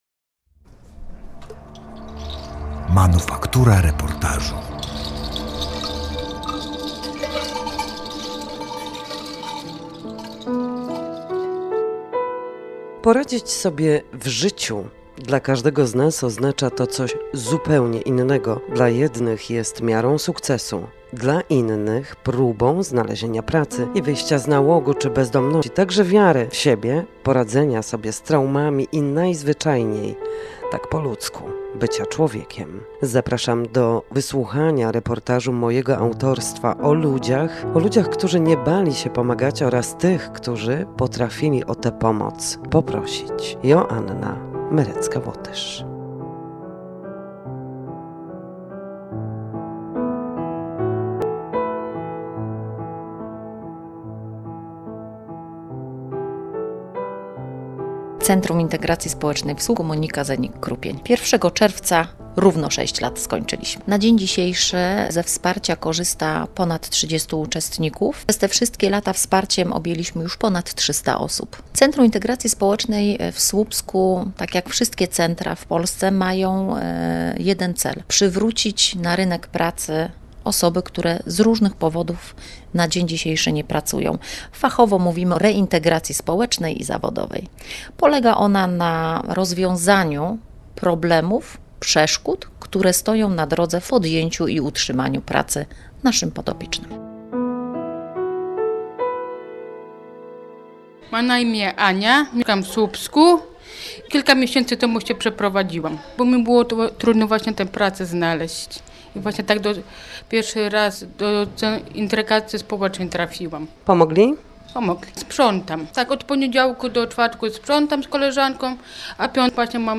Z wizytą w Centrum Integracji Społecznej w Słupsku